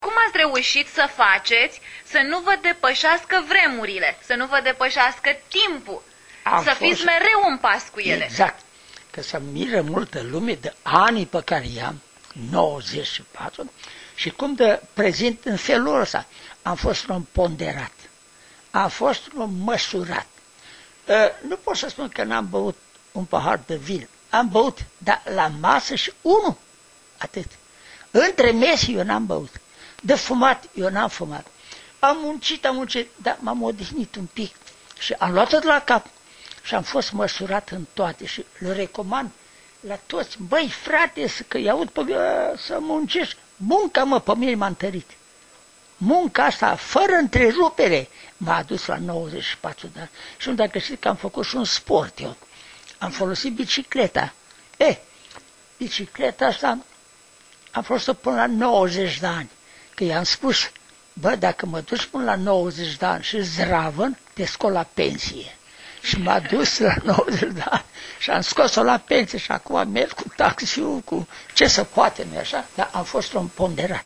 Într-un interviu realizat în anul 2007, bătrânul preot a povestit cum a făcut faţă vremurilor potrivnice şi cum munca l-a salvat întotdeauna: